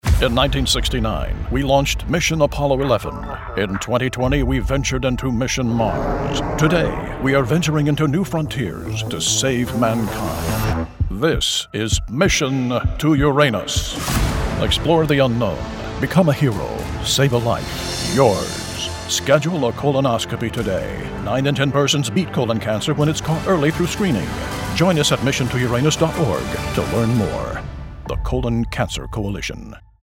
Digital Home Studio
Shure KSM 32 Large diaphragm microphone
Radio Portfolio